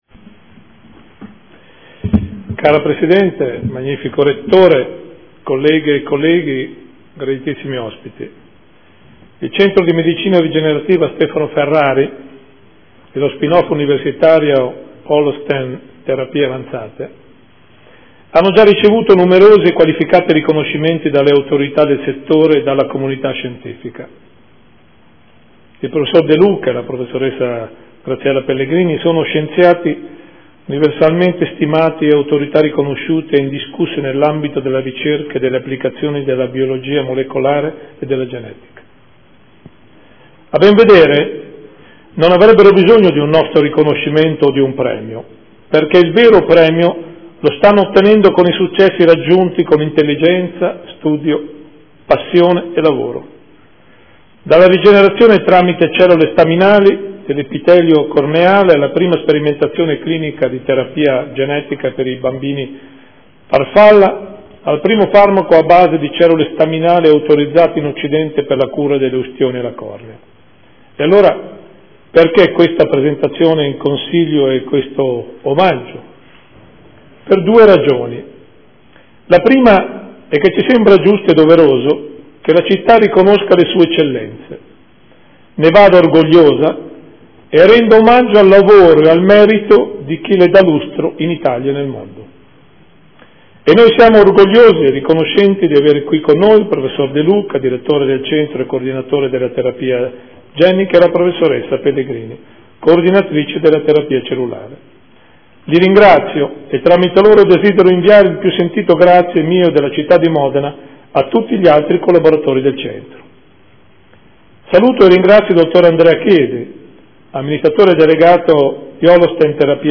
Gian Carlo Muzzarelli — Sito Audio Consiglio Comunale